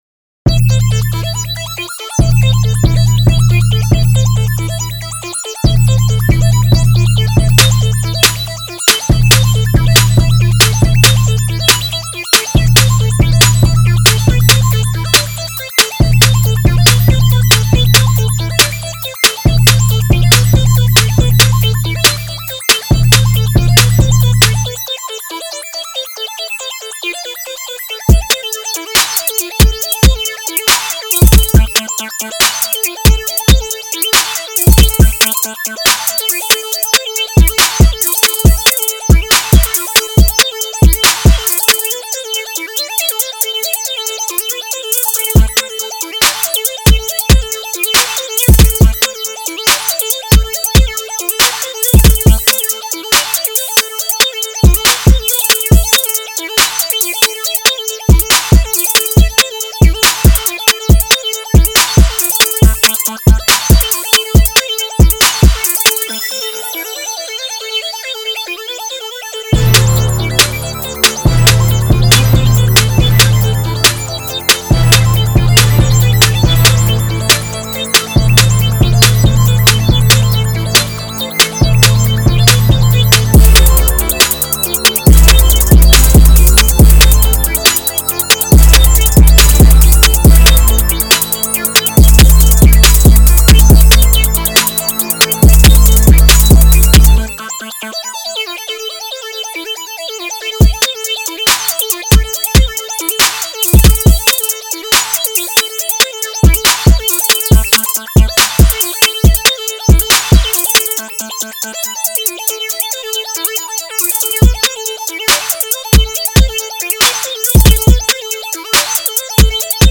808 Heavy, Synthesizer based.